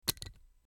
Звуки плоскогубцев
Скрип плоскогубцев при попытке вытащить застрявший гвоздь